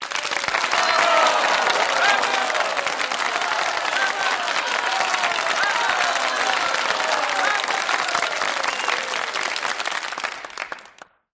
Звуки аплодисментов
Люди аплодируют и выкрикивают браво